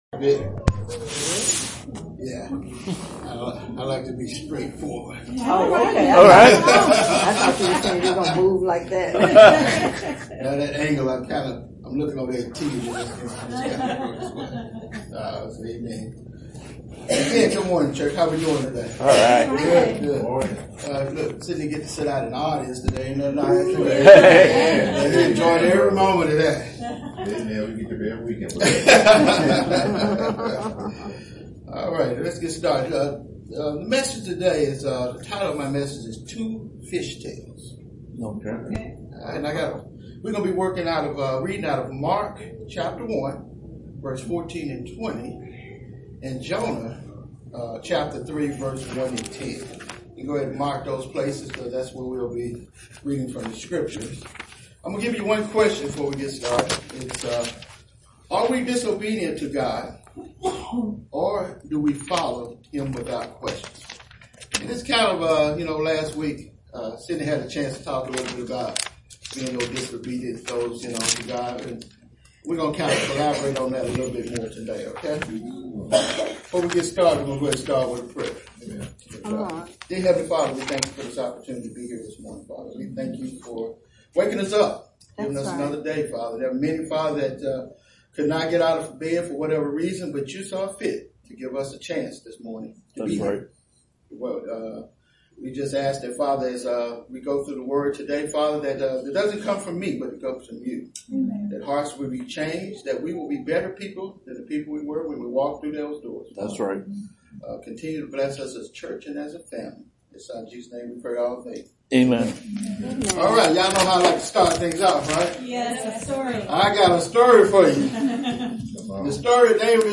Sermons | Tri-County Church